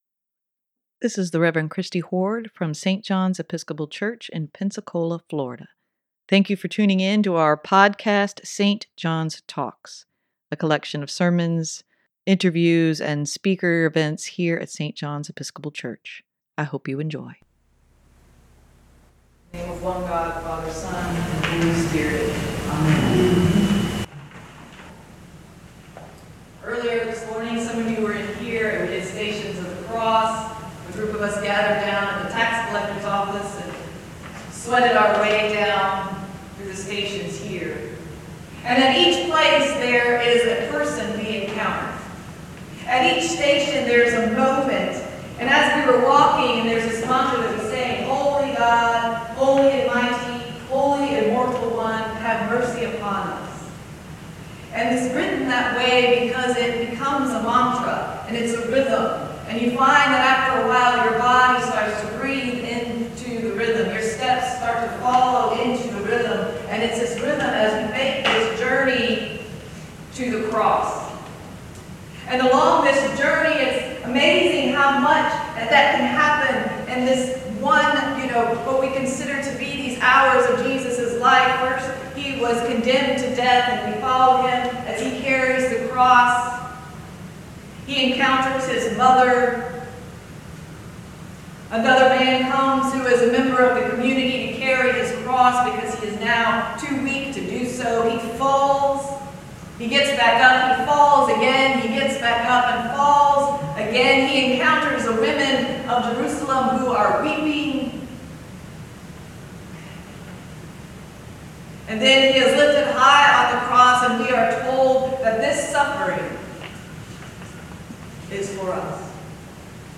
sermon-Good-Friday-2023.mp3